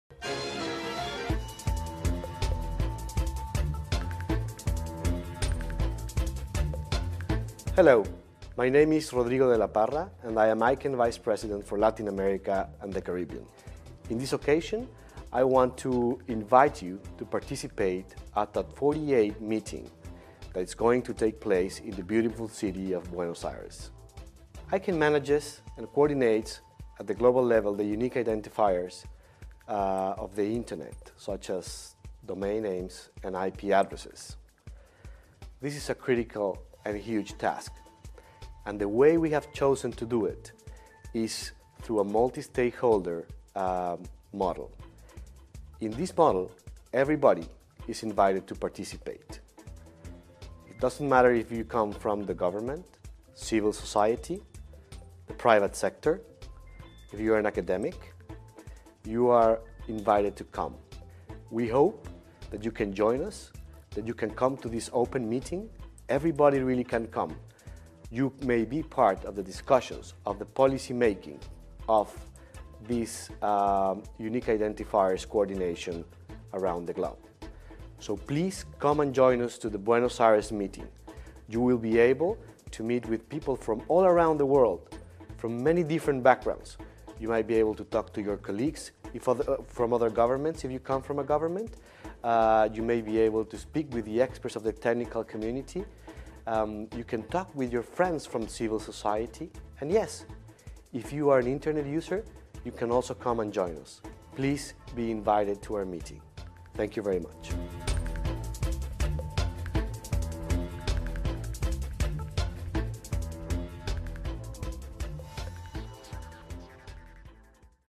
ICANN 48 | Buenos Aires | 05 Nov 2013